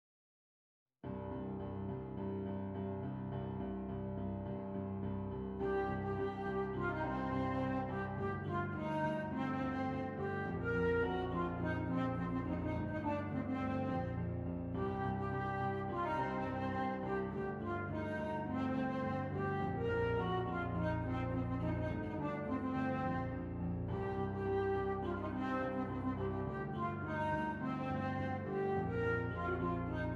Flute Solo with Piano Accompaniment
C Minor
Moderato